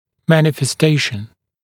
[ˌmænɪfes’teɪʃn][ˌмэнифэс’тэйшн]проявление, манифестация